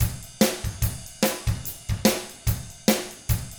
146ROCK T3-R.wav